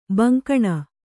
♪ gankaṇa